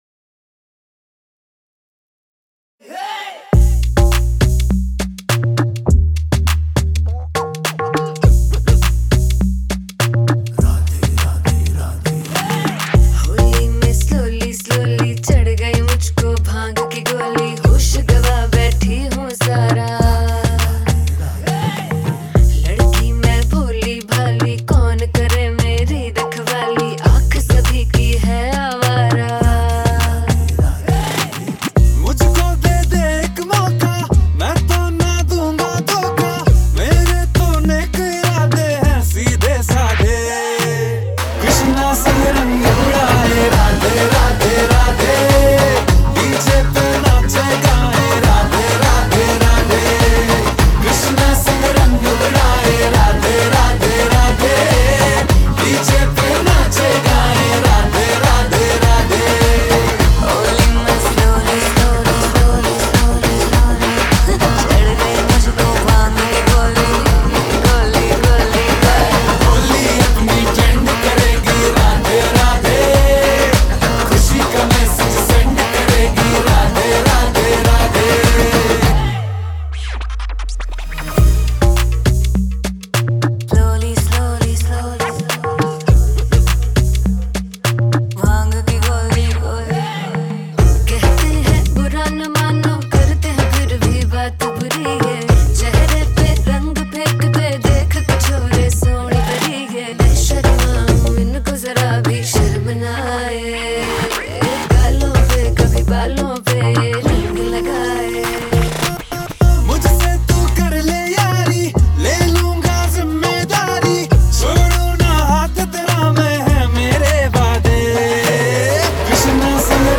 Bollywood